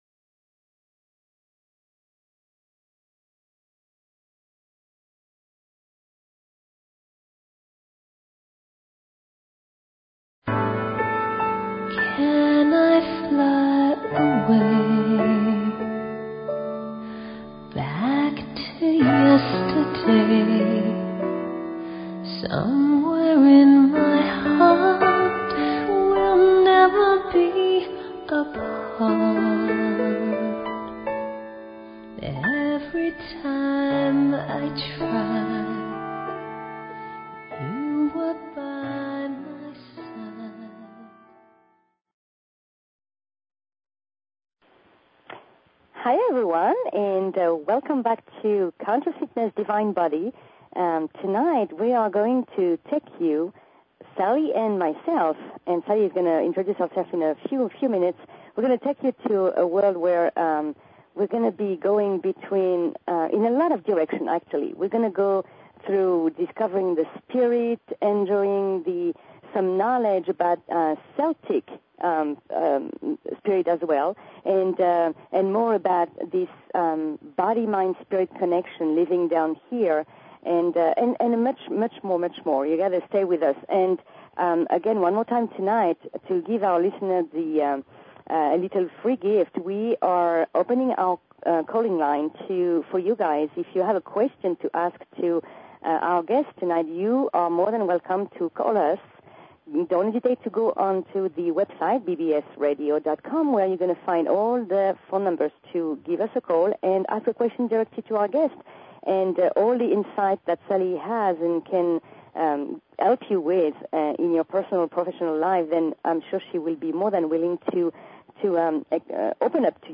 Talk Show Episode, Audio Podcast, Conscious_Fitness and Courtesy of BBS Radio on , show guests , about , categorized as